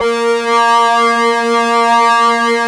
45 SYNTH 1-L.wav